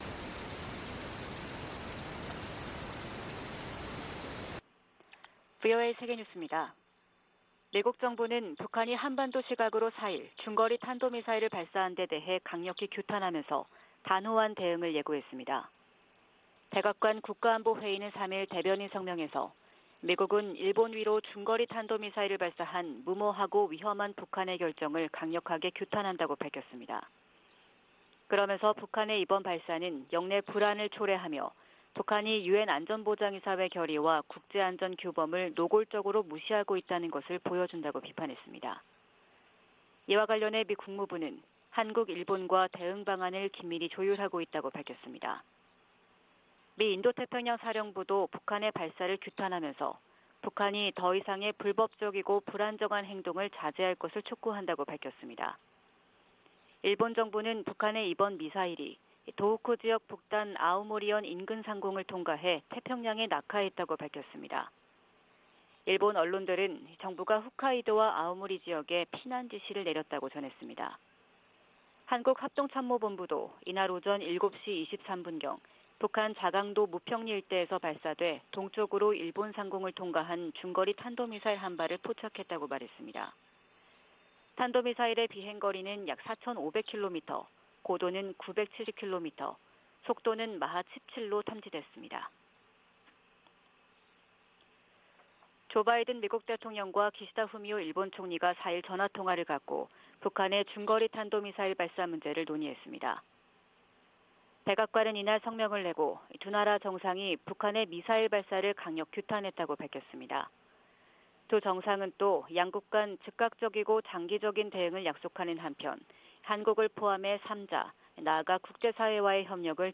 VOA 한국어 '출발 뉴스 쇼', 2022년 10월 5일 방송입니다. 북한이 4일 일본열도를 넘어가는 중거리 탄도미사일(IRBM)을 발사했습니다.